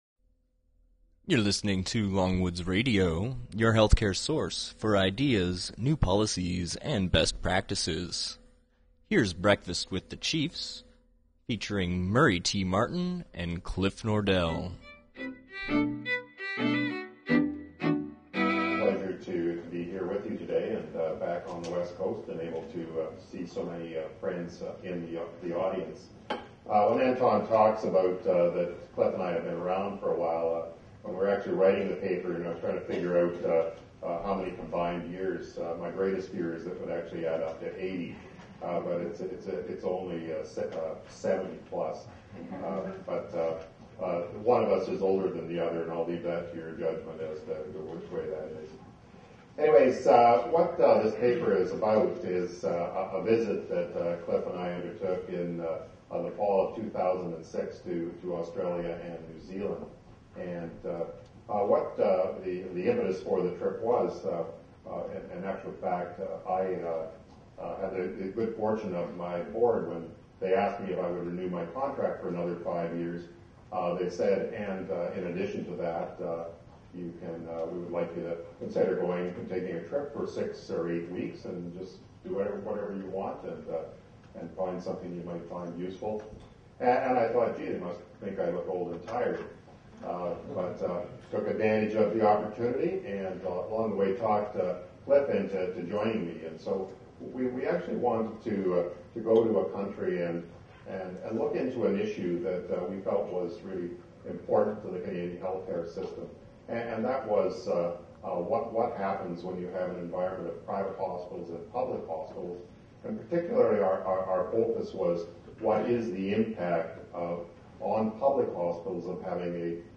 Thursday, February 12, 2009 – Vancouver UBC Robson Square